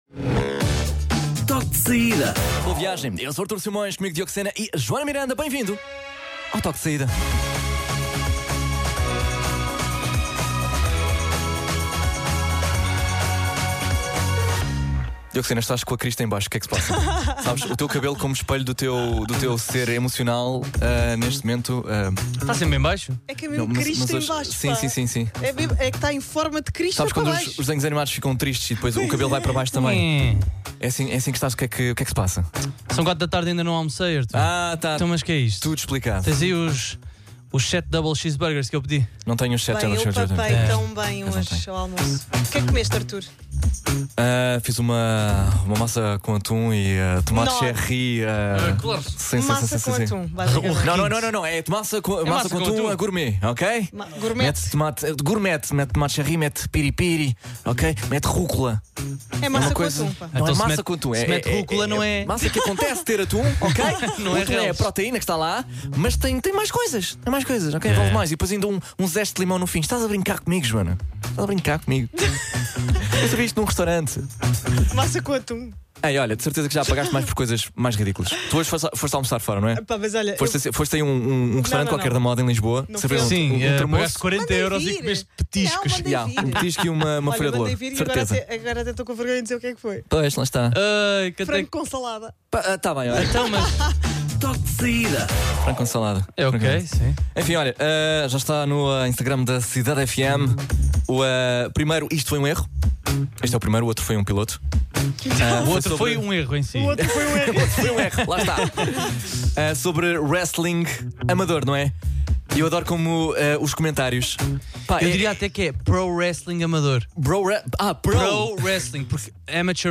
Quando ouvires o toque: tu sais da escola, eles entram em estúdio.